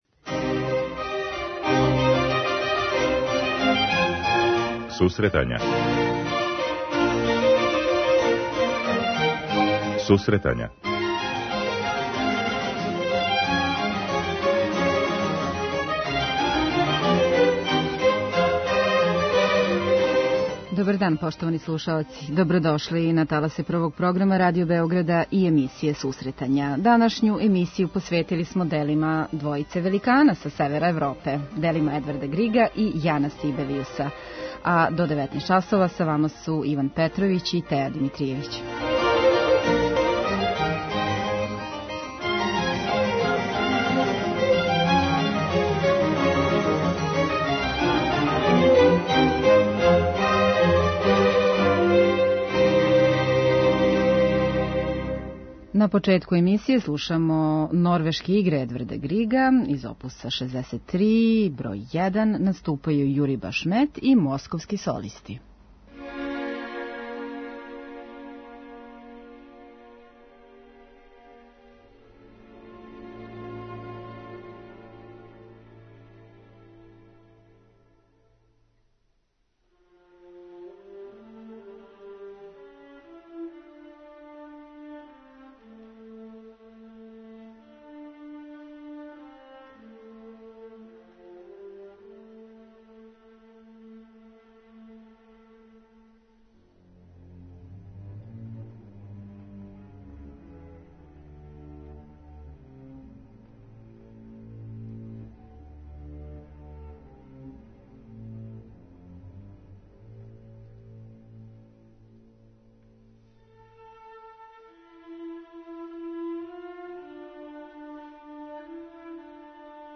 преузми : 9.79 MB Сусретања Autor: Музичка редакција Емисија за оне који воле уметничку музику.